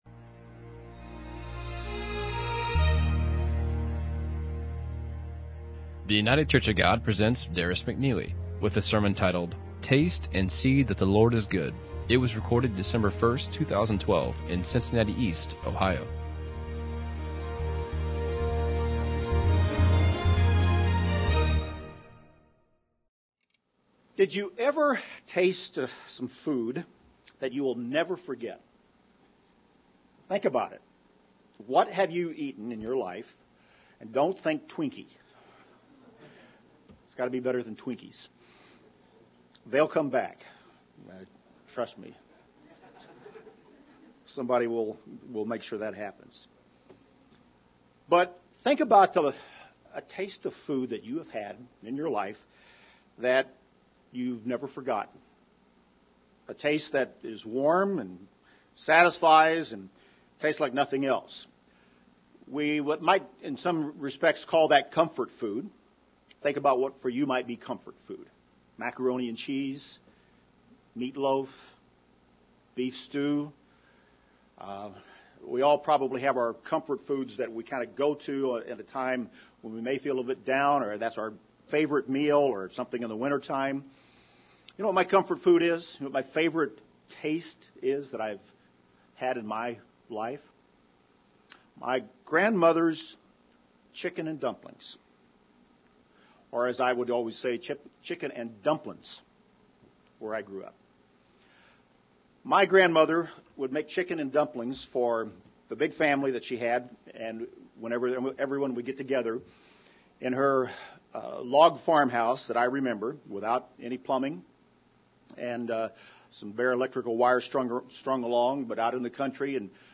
This is a sermon about tasting - because the Bible talks about tasting. The way of life that God has called us to lead, teachings of His laws and of His commandments, are something that we taste, as we live them; as we prove them - as we experience every aspect of God's way of life - we taste it (Psalm 34:8).